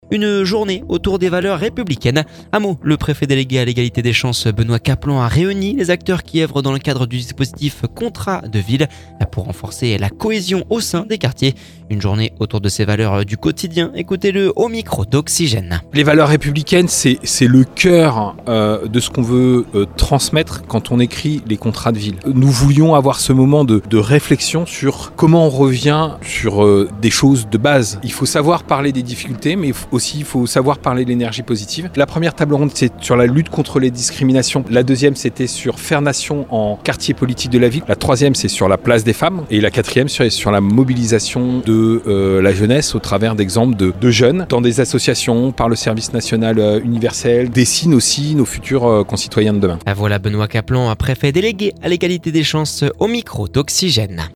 Une journée autour de ces valeurs du quotidien, écoutez-le au micro d’Oxygène…